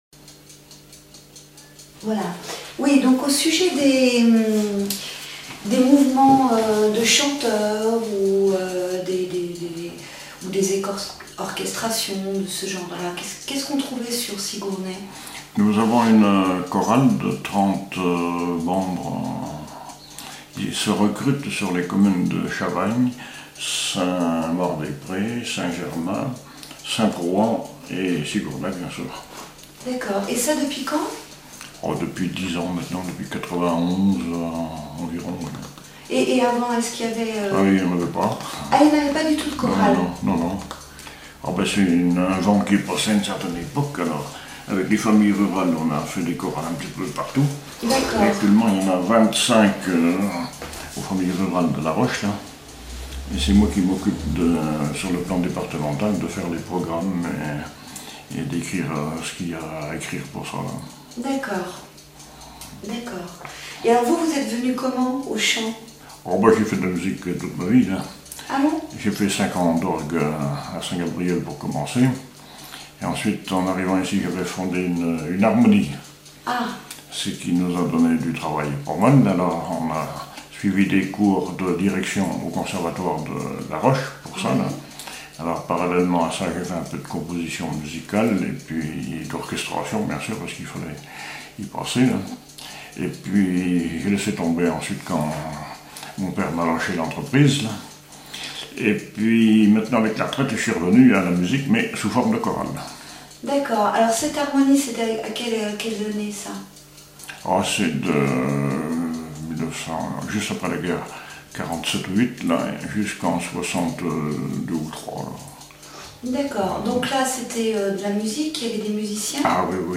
témoignages sur la musique et une chanson
Catégorie Témoignage